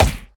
tableheadsmash.ogg